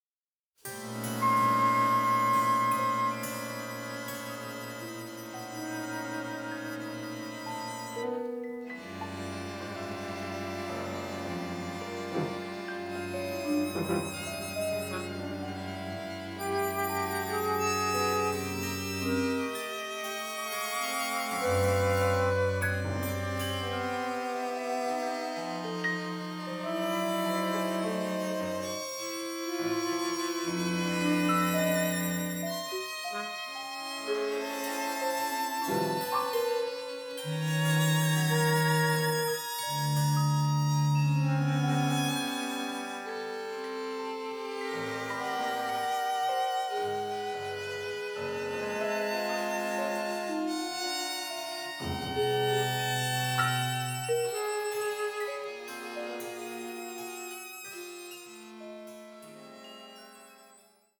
melancholic passages with atonal and dissonant moments